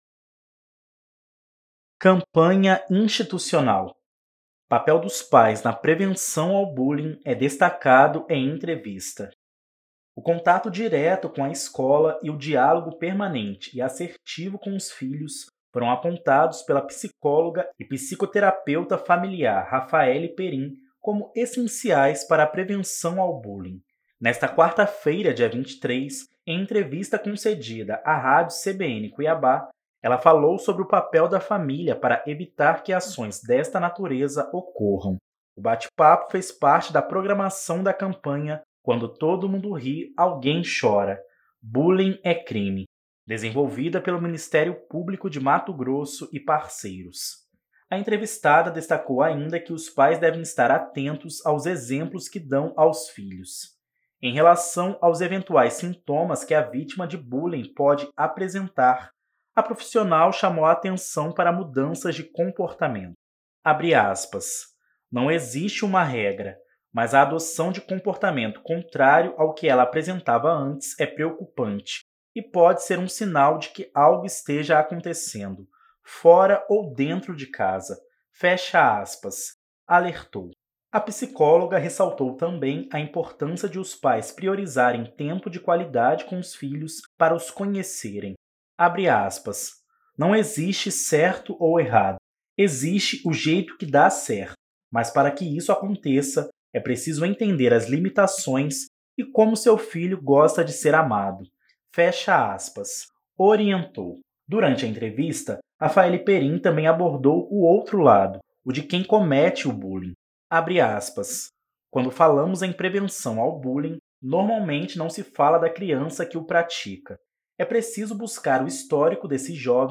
Papel dos pais na prevenção ao bullying é destacado em entrevista
Papel dos pais na prevenção ao bullying é destacado em entrevista.mp3